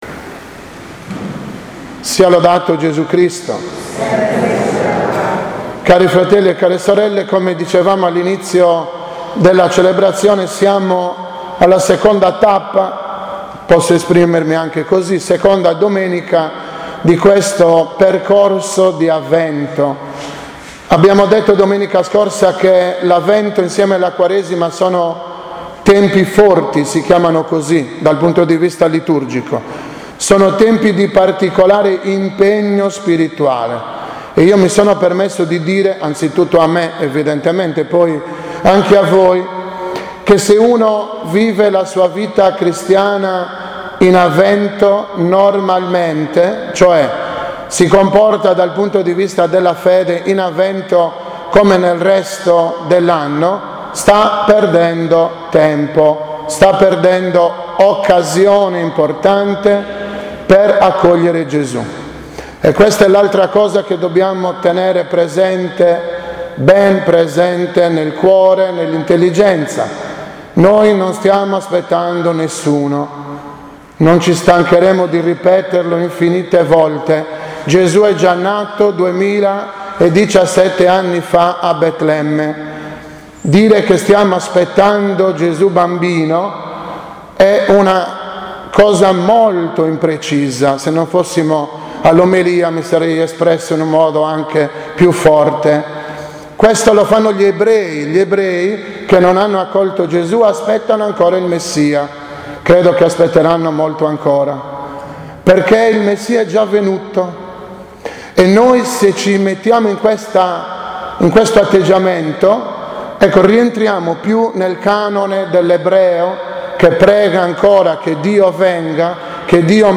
10.12.2017 OMELIA DELLA II DOMENICA DI AVVENTO B
2017-OMELIA-II-DI-AVVENTO-B.mp3